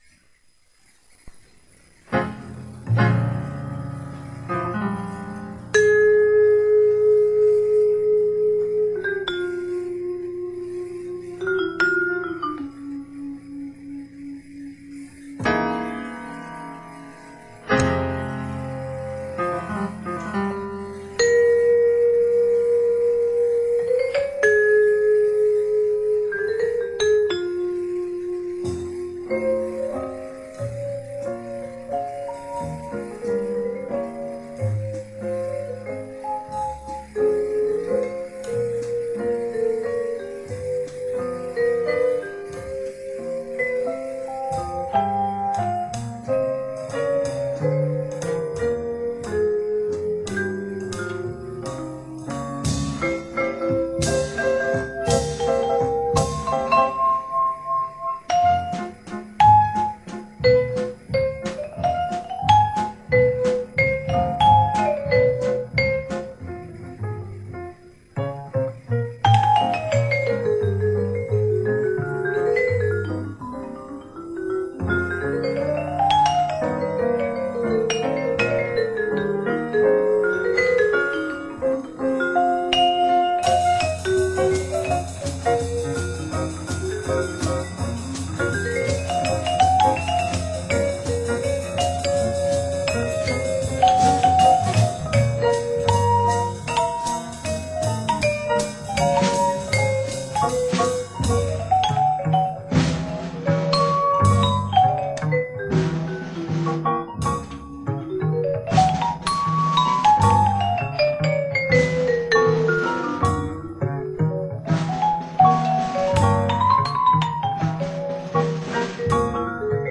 Recorded on June 22, 1986 in Tübingen, Germany
piano
vibraphone
double bass
drums